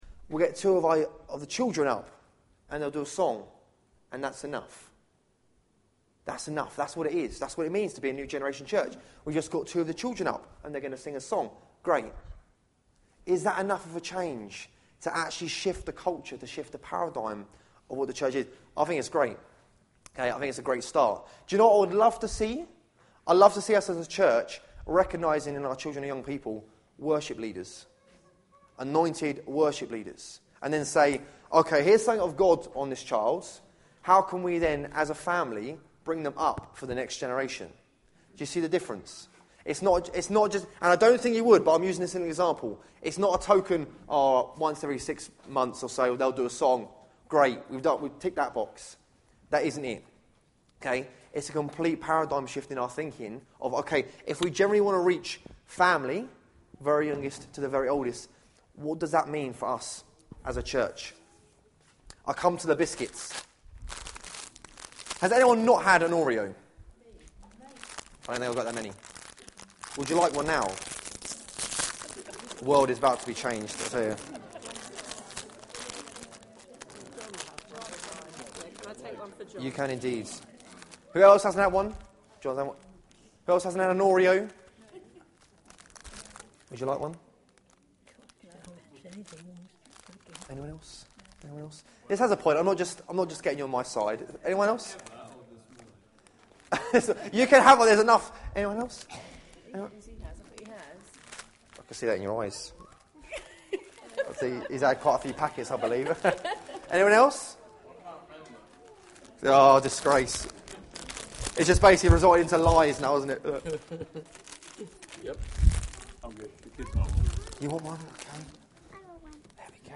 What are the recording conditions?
Judges 21:25 Service Type: Sunday Morning « Meeting our Demographic